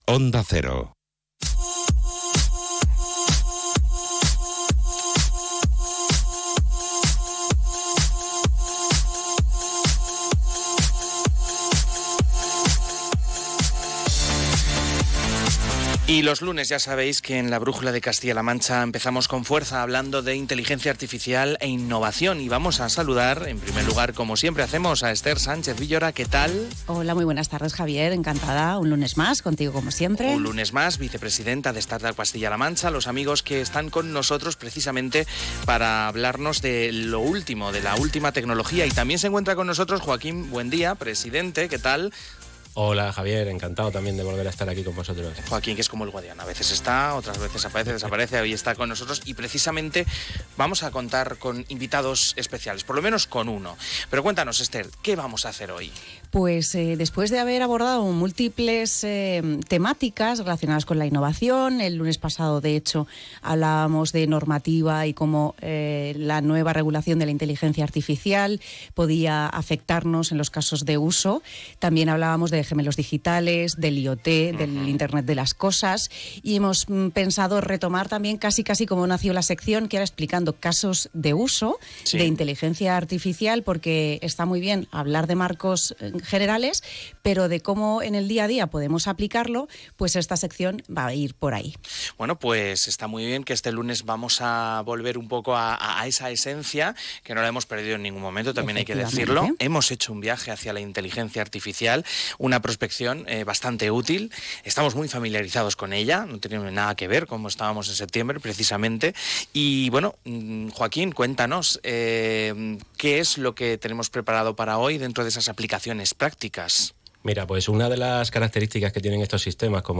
¿Te imaginas practicar tus habilidades comerciales con una inteligencia artificial que habla como un vecino cercano?
Durante la sesión se utilizó la capacidad de voz avanzada de ChatGPT en un role-playing en el que un comercial y un cliente exigente interactuaban de forma natural y casi íntima.